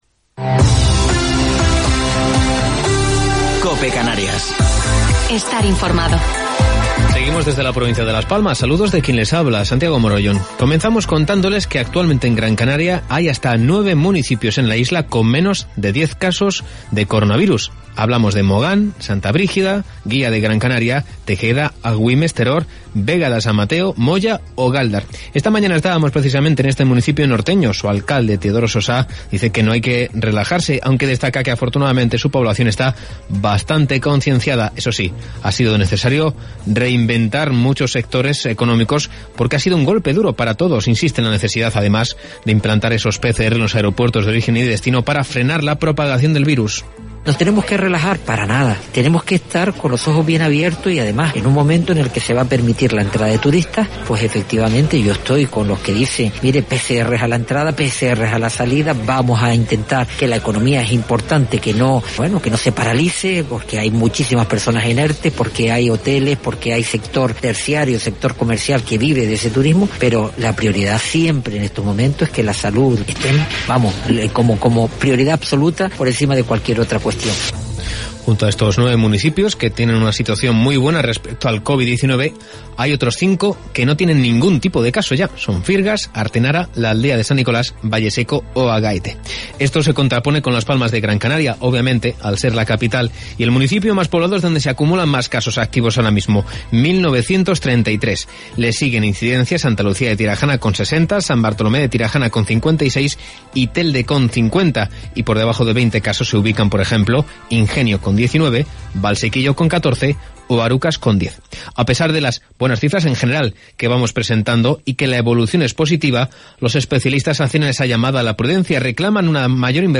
Informativo local 3 de Noviembre del 2020